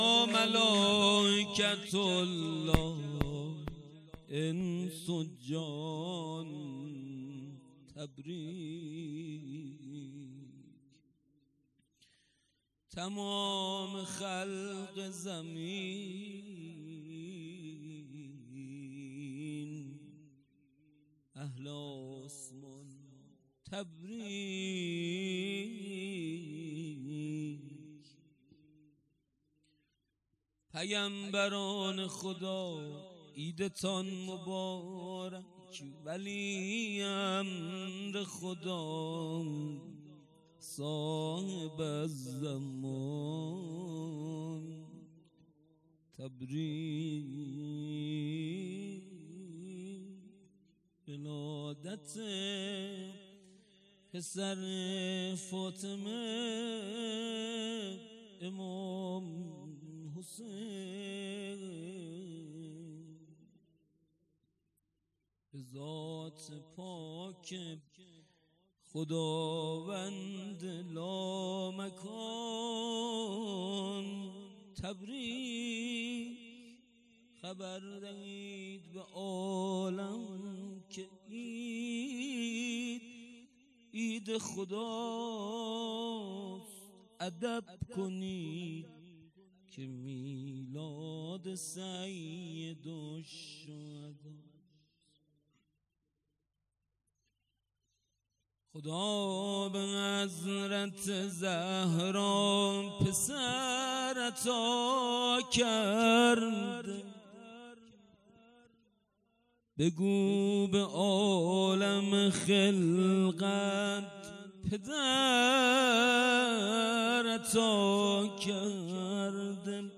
خیمه گاه - هیئت ذبیح العطشان کرمانشاه - ولادت امام حسین(ع)-مدح امام حسین(ع)
هیئت ذبیح العطشان کرمانشاه